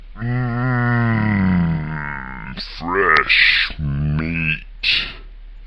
描述：深沉的男声（魔鬼或怪物？）说，"嗯，新鲜的肉！"吓人。
Tag: 语音 地狱 邪恶 警告 窃窃私语 判断 声乐 超自然 万圣节 邪恶 恐怖 怪异 怪物 魔鬼 可怕